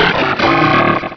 sovereignx/sound/direct_sound_samples/cries/crawdaunt.aif at 6b8665d08f357e995939b15cd911e721f21402c9